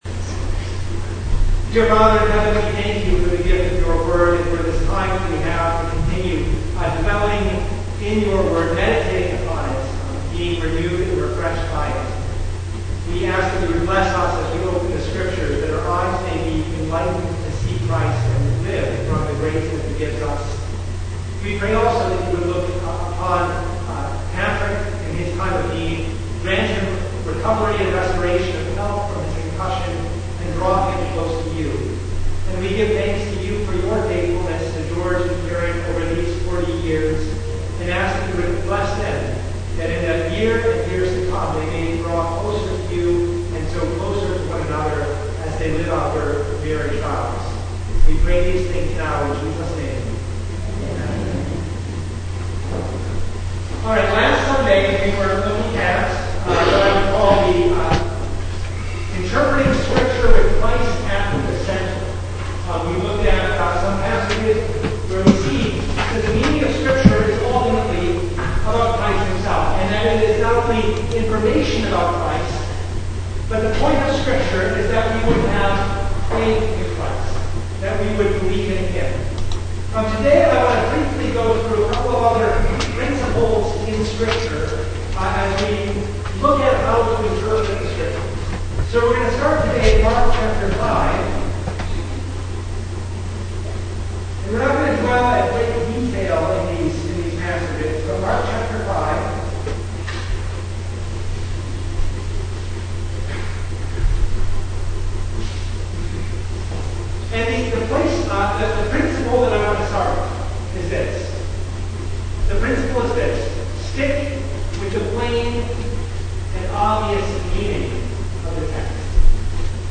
Recording heavily edited due to problems with recording equipement.
Service Type: Bible Study